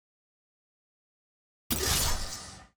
sfx-tier-wings-promotion-from-silver.ogg